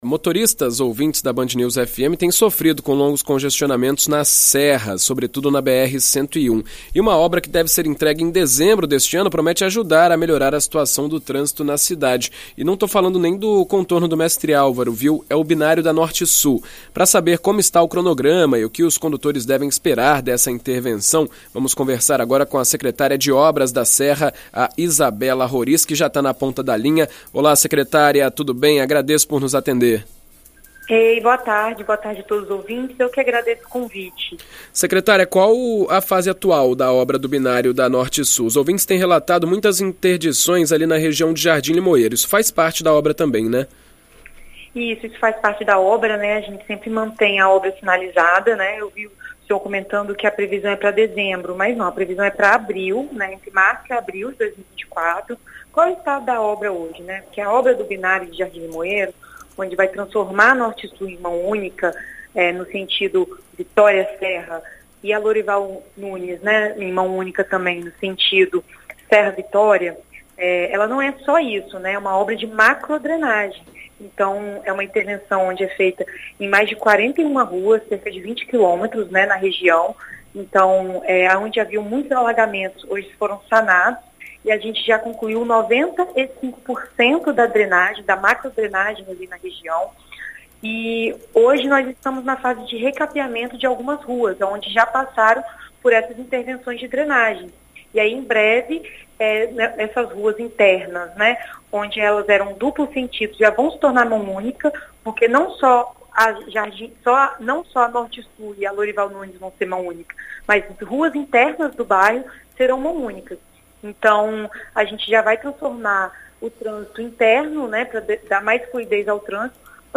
Em entrevista à rádio BandNews FM ES, a secretária de Obras da Serra, Izabela Roriz, deu detalhes sobre o andamento dos trabalhos na região e a expectativa de melhora no trânsito local.